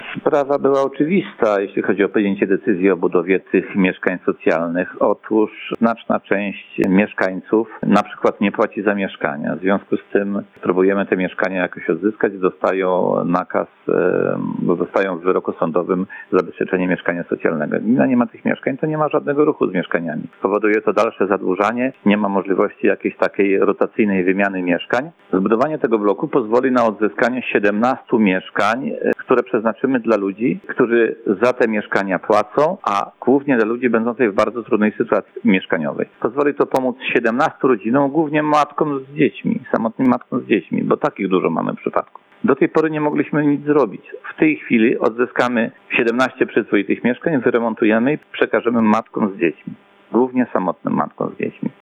Andrzej Szymborski, burmistrz Pisza, wyjaśnia cel budowy bloku przy ulicy Nidzkiej.